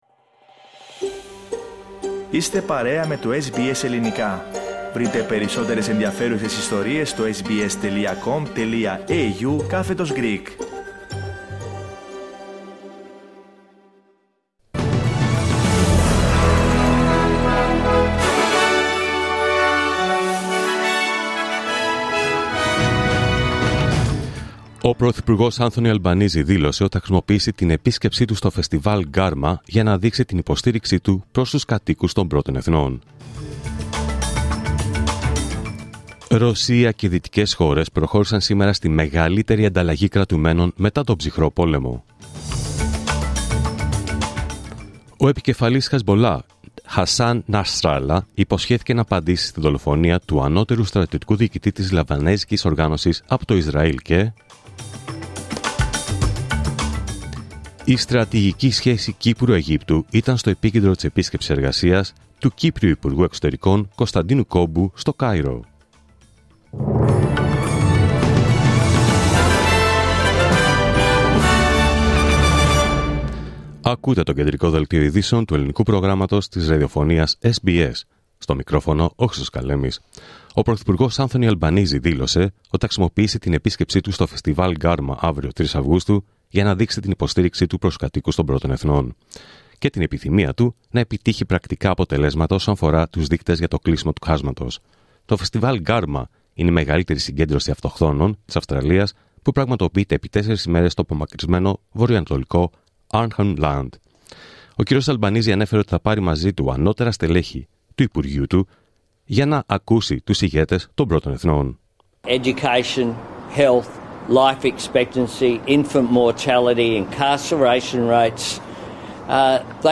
Δελτίο Ειδήσεων Παρασκευή 2 Αυγούστου 2024